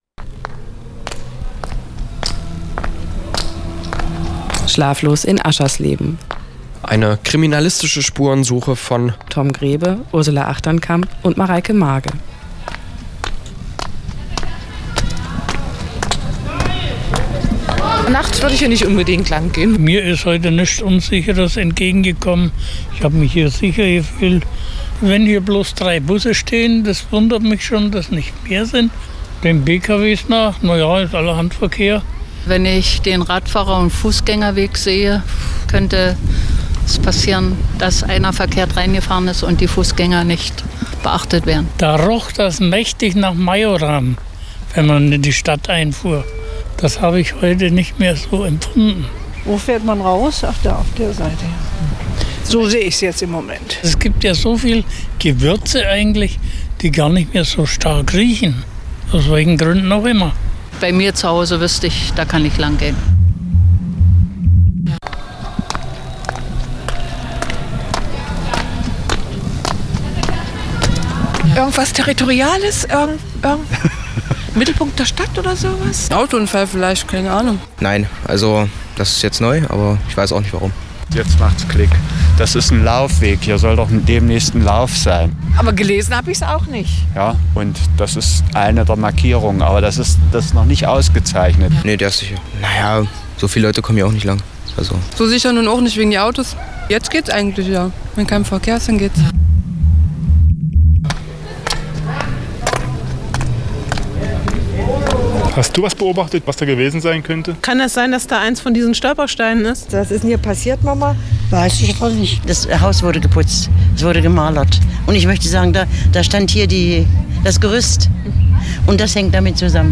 Das Kriminalhörspiel sei ein Klassiker der Radiounterhaltung, so die Jury.
Gemeinsam mit den Bürgern aus Aschersleben machten sie sich auf die Suche: Ist das der Mittelpunkt der Stadt oder gar des Landes, eine Wegmarkierung für Wanderer oder hat hier gar ein Verbrechen stattgefunden? Die Fantasie der befragten Passanten ist grenzenlos. Reduziert auf deren Antworten lassen die Radiomacher ihre Hörer mit der Suche nach der Frage allein.
Besondere Erwähnung verdient die dramaturgisch geschickt eingesetzte musikalische Untermalung.